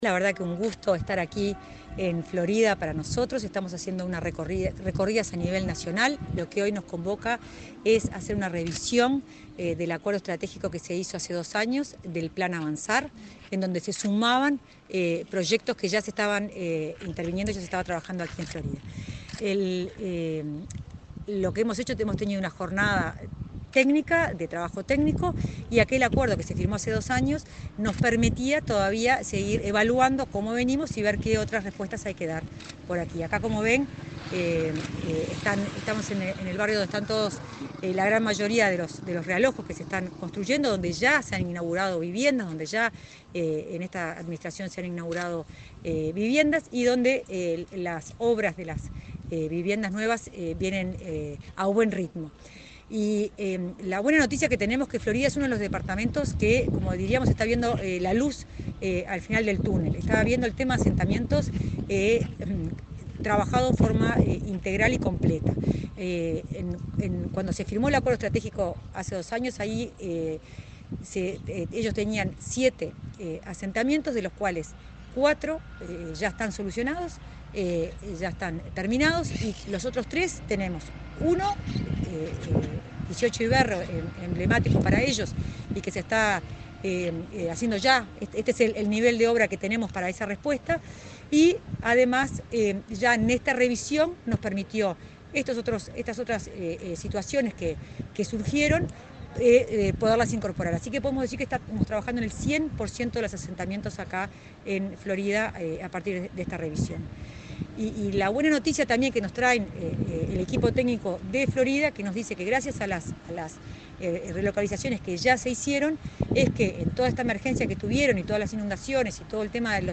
Entrevista a la directora de Integración Social y Urbana del Ministerio de Vivienda, Florencia Arbeleche
Entrevista a la directora de Integración Social y Urbana del Ministerio de Vivienda, Florencia Arbeleche 19/07/2024 Compartir Facebook X Copiar enlace WhatsApp LinkedIn Este viernes 19, la directora de Integración Social y Urbana del Ministerio de Vivienda, Florencia Arbeleche, dialogó con Comunicación Presidencial en Florida, donde realizó una revisión de acuerdos estratégicos con la intendencia local acerca del plan Avanzar.